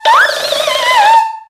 infinitefusion-e18/Audio/SE/Cries/PHANTUMP.ogg at a50151c4af7b086115dea36392b4bdbb65a07231